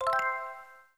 Sega NAOMI Startup Phase 1.wav